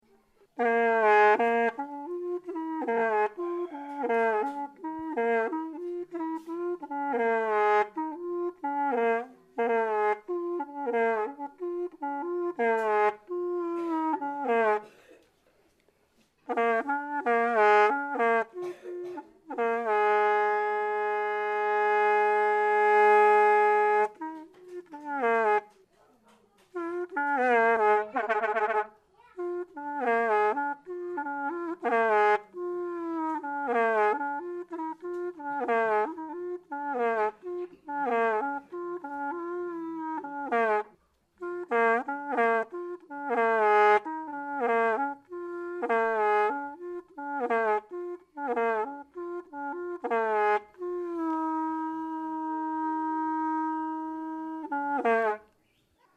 bass reed pipe solo recorded in Luang Prabang 878KB
Hmong LP bass reed pipe.mp3